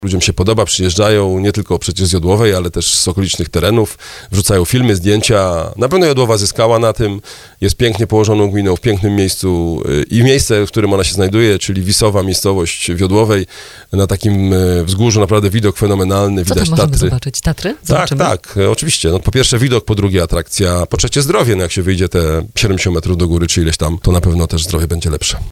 chwali starosta dębicki Piotr Chęciek.